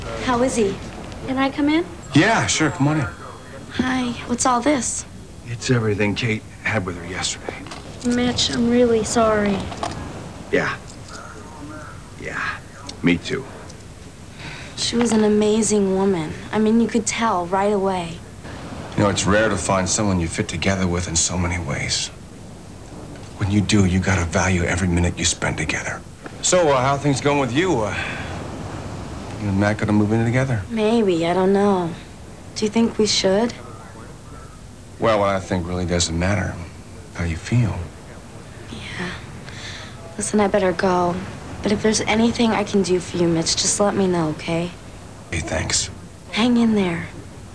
nicole.au [1Mb] Archivo de sonido sacado del capitulo "The Falcon Manifesto" de Baywatch [1994]. Summer hablando con Mitch (claro que en ingles).